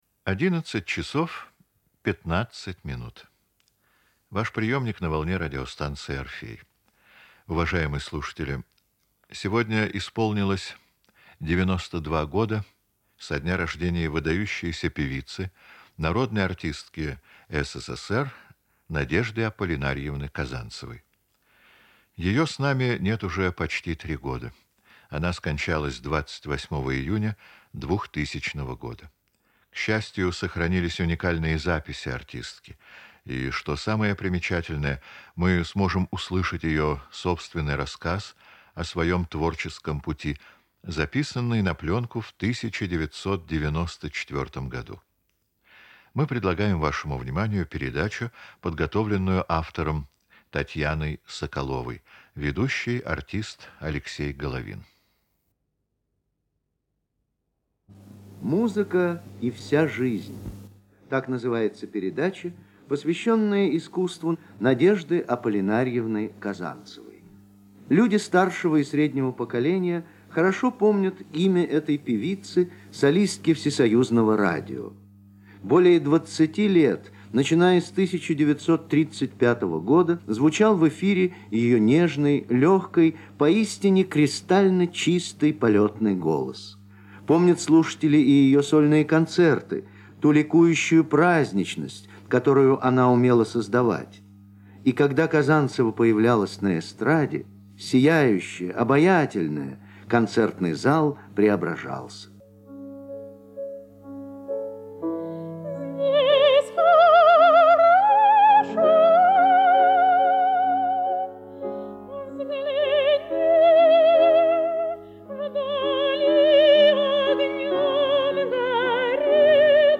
Фрагменты радиопередач с участием певицы.
Передача записана с УКВ